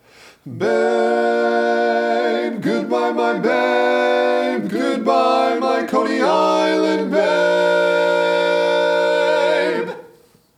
Other part 1: